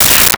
Space Gun 06
Space Gun 06.wav